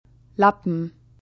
Plosive